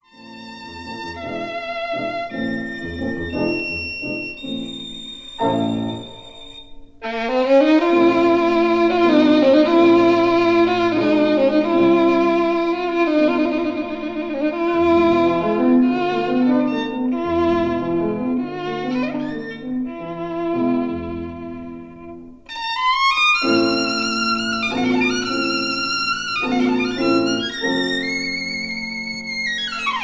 No. 7 in A minor
violin
piano